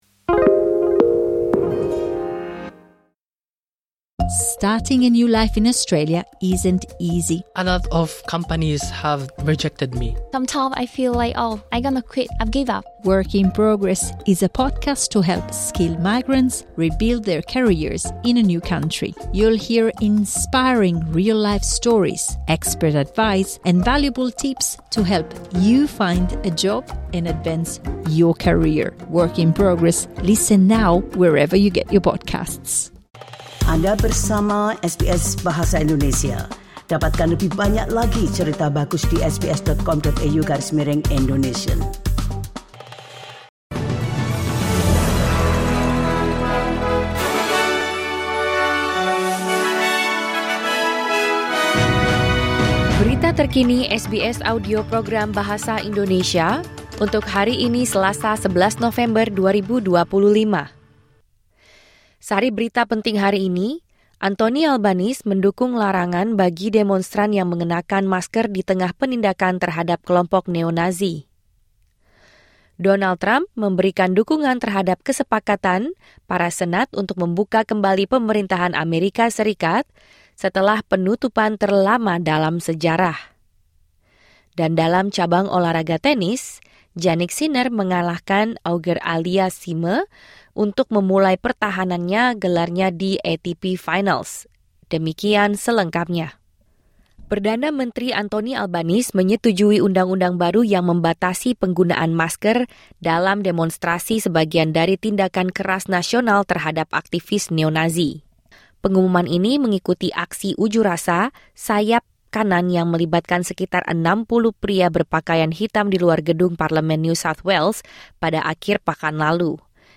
Berita Terkini SBS Audio Program Bahasa Indonesia – 11 November 2025